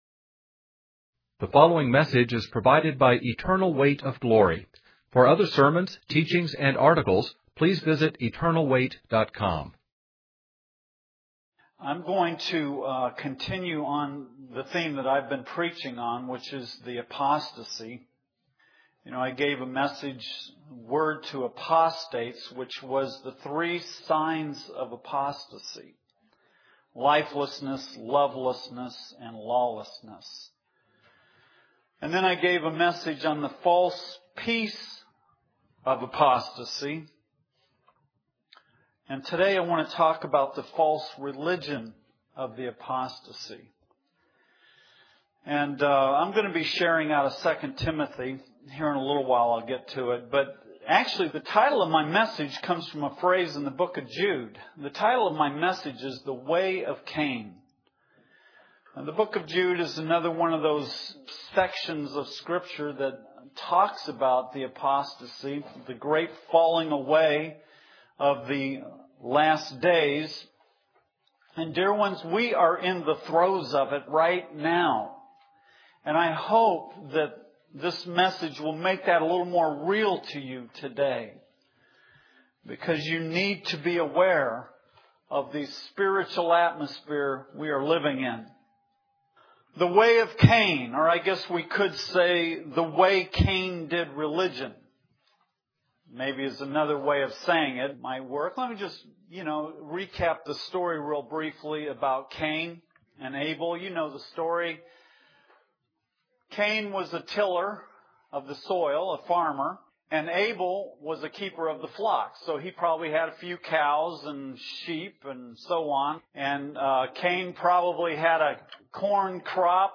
In this sermon, the preacher discusses the attitudes and behaviors of people in the end times as described in the Bible. He highlights four phrases that describe these individuals: lovers of self, swept away with worldly influences, deceived and deceiving others, and holding to a form of godliness. The preacher emphasizes that the answer to overcoming these tendencies is not through personal effort, but through surrendering to God.